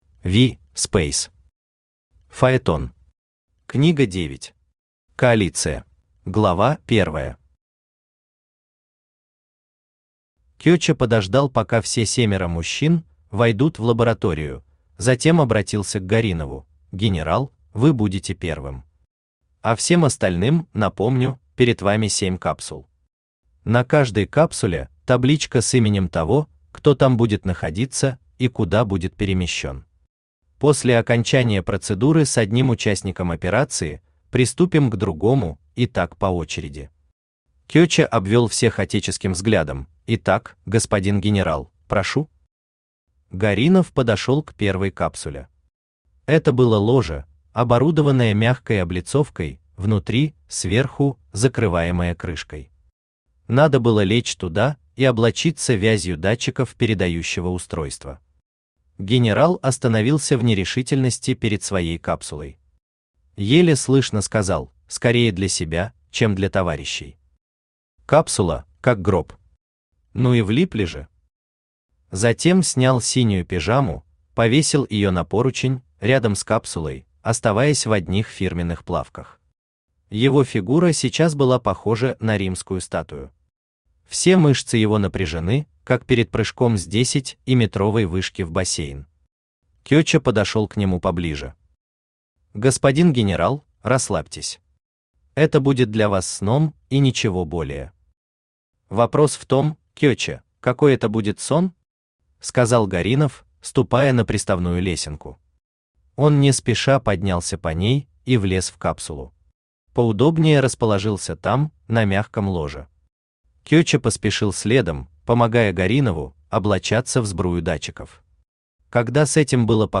Аудиокнига Фаетон. Книга 9. Коалиция | Библиотека аудиокниг
Коалиция Автор V. Speys Читает аудиокнигу Авточтец ЛитРес.